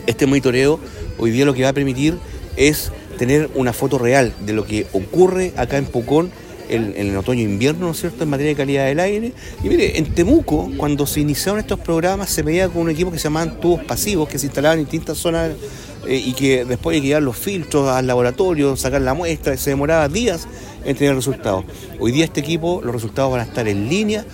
El seremi de Medio Ambiente en La Araucanía, Félix Contreras, explicó que el análisis de la calidad del aire permitirá tener datos en línea.